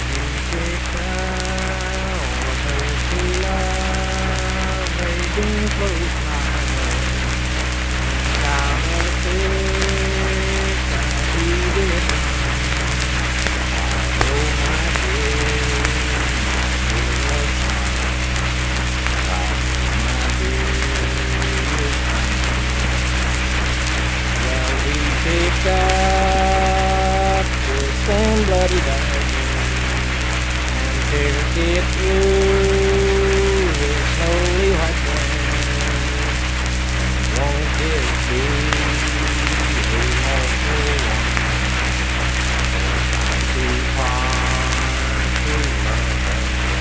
Unaccompanied vocal music
Verse-refrain 2(4).
Voice (sung)
Pleasants County (W. Va.), Saint Marys (W. Va.)